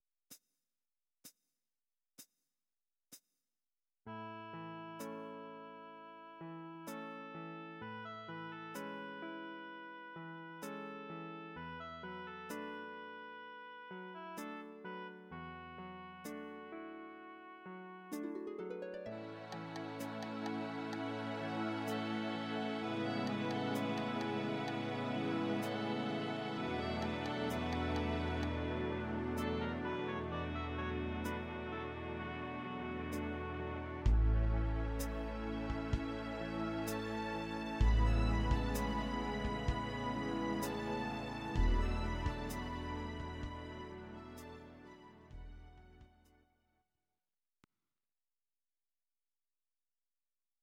Please note: no vocals and no karaoke included.
Your-Mix: Disco (724)